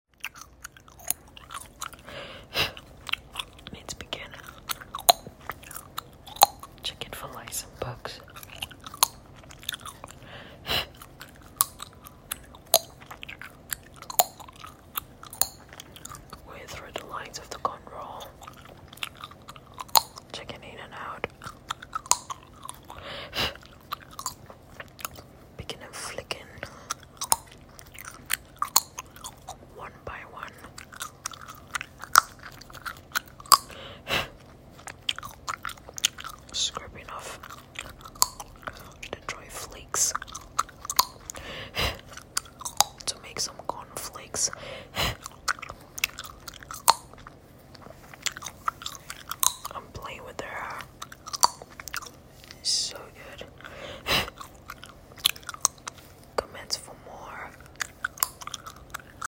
Dandruff And Lice Eggs Picking Sound Effects Free Download